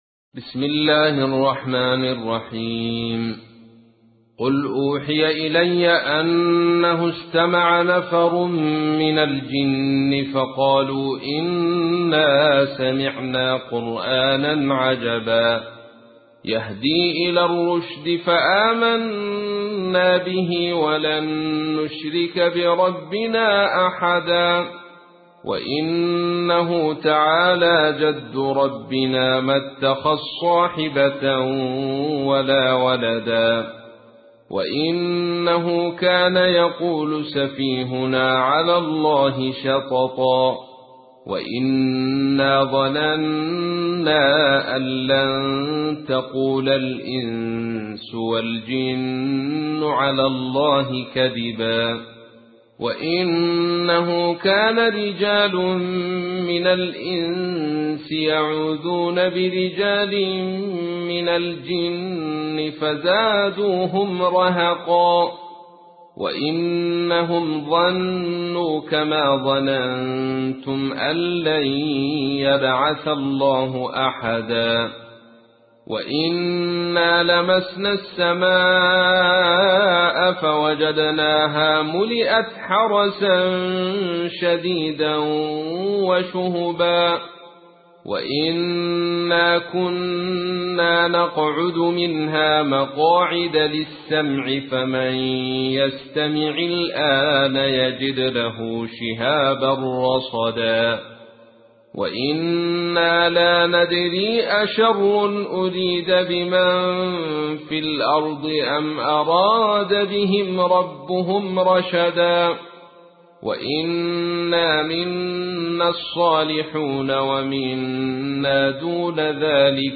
تحميل : 72. سورة الجن / القارئ عبد الرشيد صوفي / القرآن الكريم / موقع يا حسين